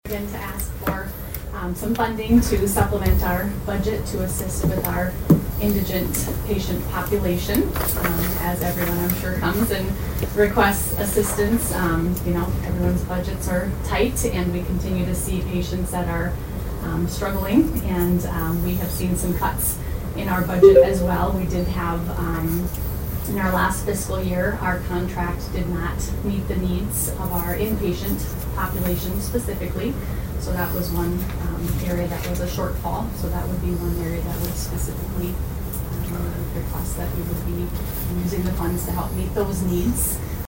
ABERDEEN, S.D.(HubCityRadio)-  At Tuesday’s Brown County Commission meeting, Avera St. Luke’s Behavorial Health & Addiction Care Services requested to be included in the upcoming budget for 2026.